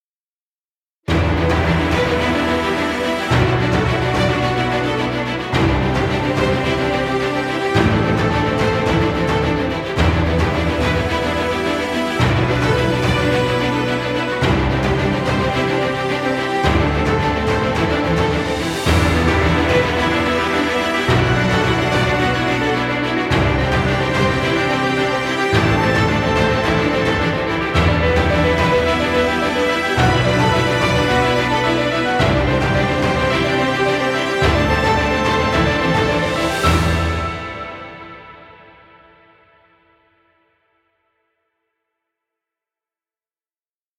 Epic dramatic music.